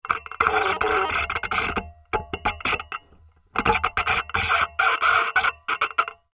Звуки жесткого диска
Шумы неисправного жесткого диска